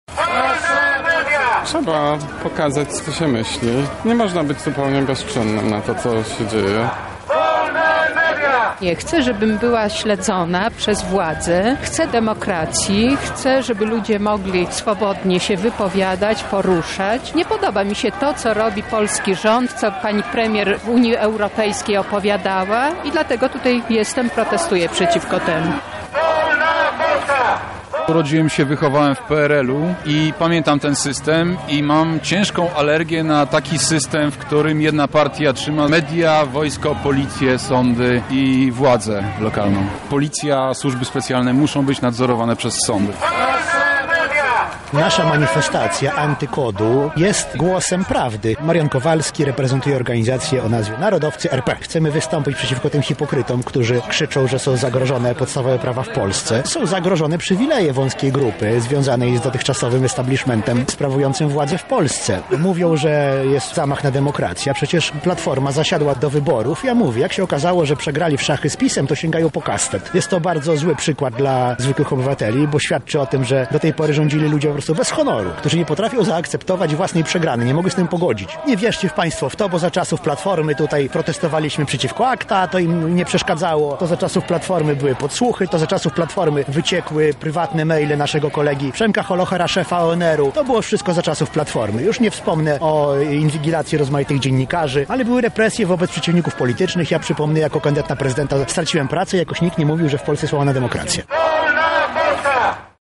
Na miejscu była nasza reporterka.
Obie strony zaopatrzone w plakaty i transparenty skandowały hasła wyrażające ich poglądy.